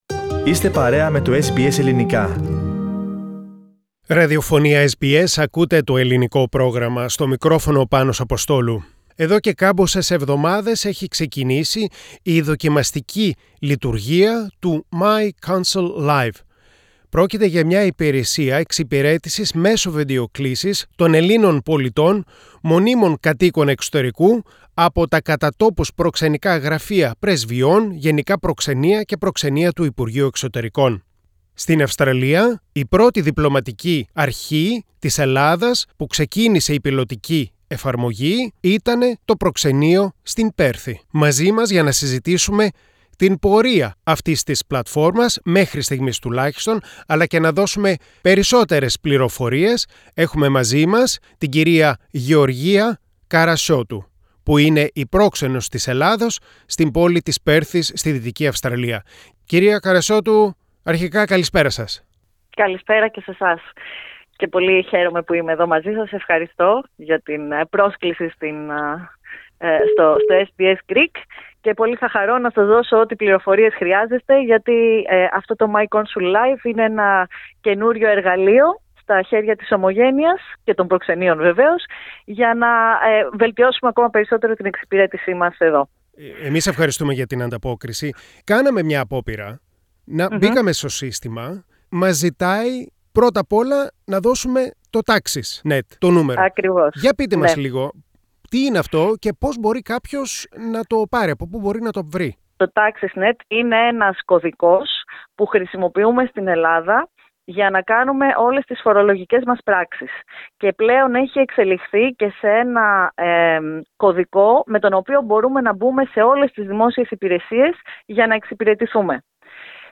Η Πρόξενος της Ελλάδας στην Πέρθη, Γεωργία Καρασιώτου, δίνει αναλυτικές πληροφορίες για την πλατφόρμα εξυπηρέτησης Ελλήνων πολιτών και κατοίκων εξωτερικού, myConsulLive.